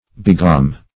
Search Result for " begohm" : The Collaborative International Dictionary of English v.0.48: Begohm \Beg"ohm`\, n. (Elec.) A unit of resistance equal to one billion ohms, or one thousand megohms.